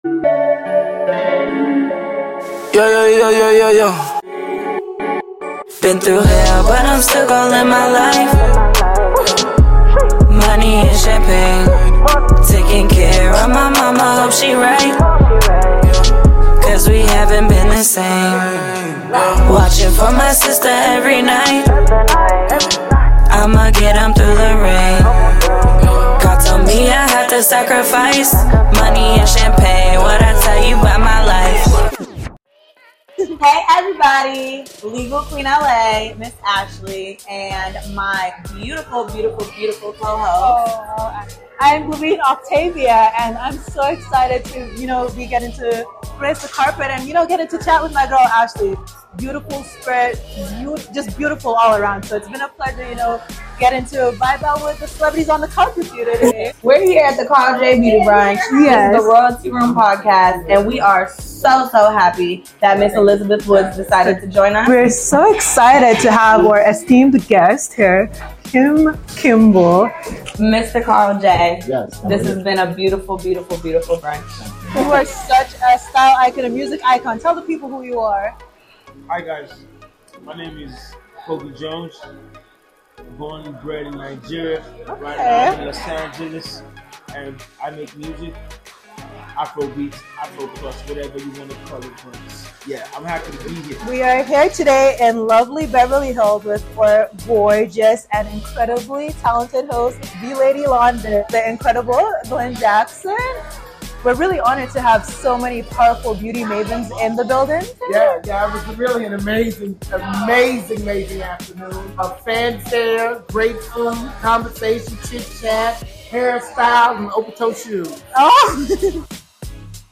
Interviews
Royalty Room hit the road again for BET Award Weekend 2023!